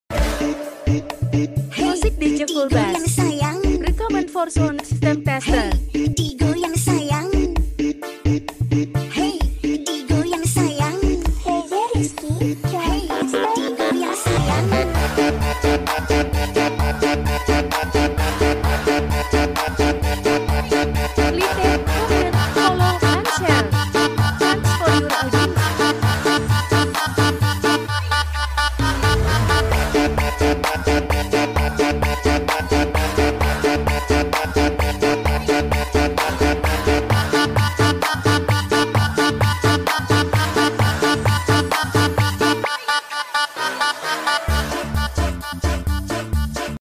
Recommended for sound system tester